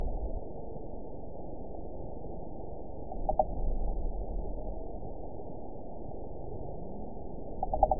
event 911600 date 03/05/22 time 03:49:45 GMT (3 years, 2 months ago) score 8.79 location TSS-AB05 detected by nrw target species NRW annotations +NRW Spectrogram: Frequency (kHz) vs. Time (s) audio not available .wav